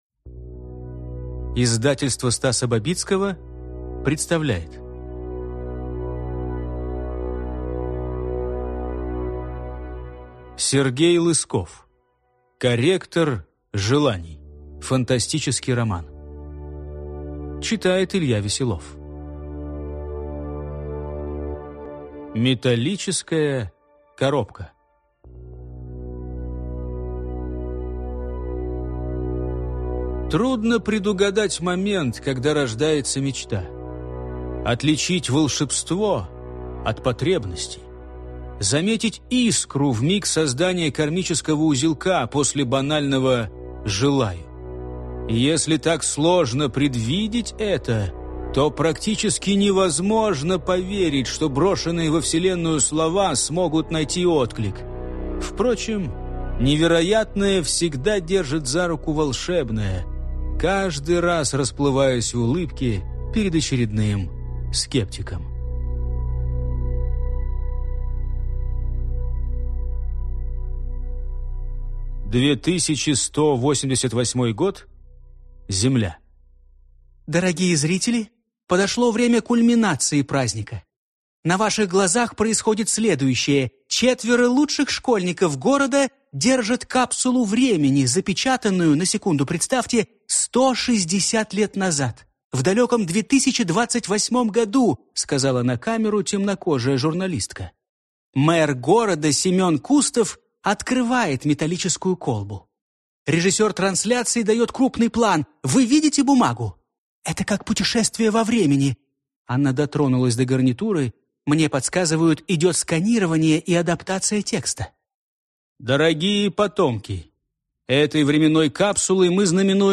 Аудиокнига Корректор желаний | Библиотека аудиокниг